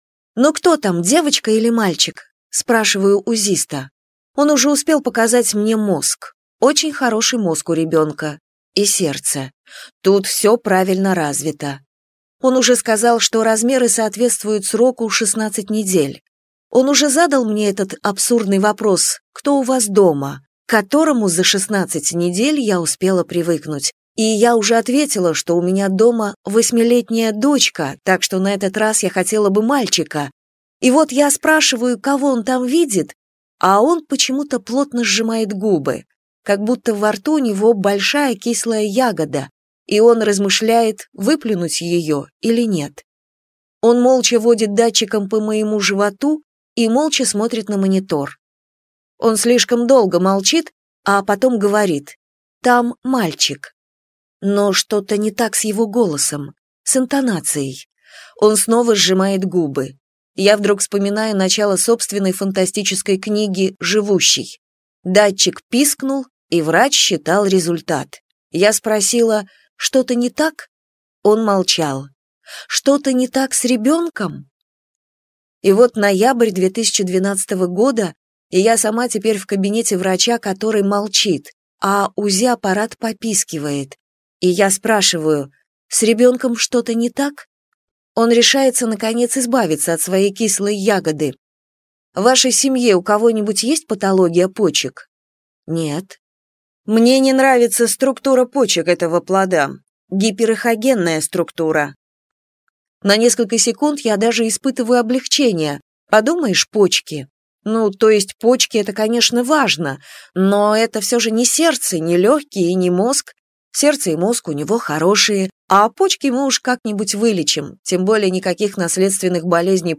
Аудиокнига Посмотри на него - купить, скачать и слушать онлайн | КнигоПоиск